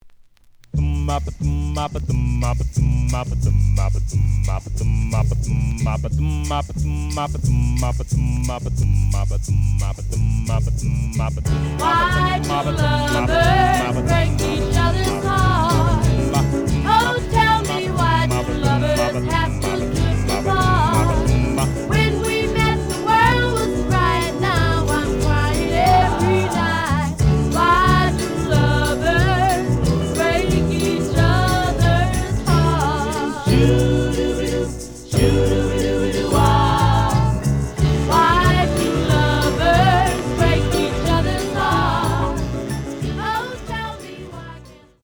試聴は実際のレコードから録音しています。
●Genre: Rhythm And Blues / Rock 'n' Roll
●Record Grading: EX- (盤に若干の歪み。多少の傷はあるが、おおむね良好。)